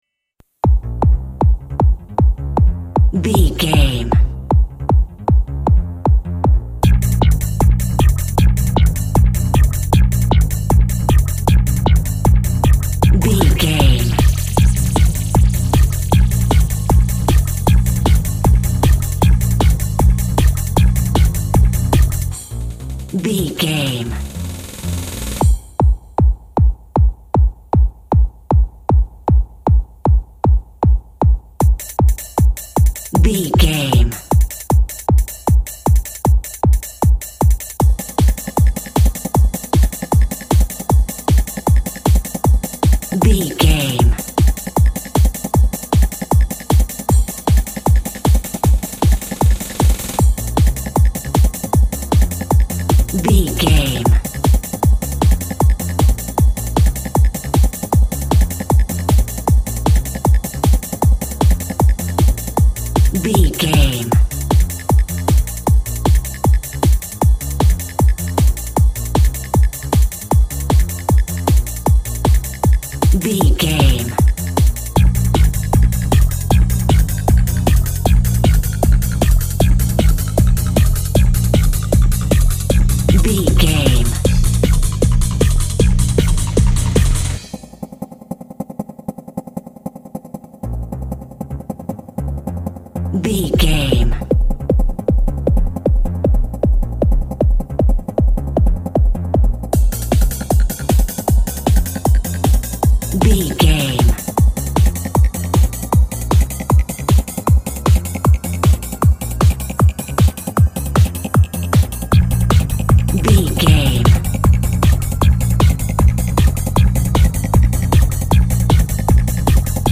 In-crescendo
Aeolian/Minor
B♭
Fast
futuristic
hypnotic
energetic
uplifting
electronic
synth lead
synth bass
Electronic drums
Synth pads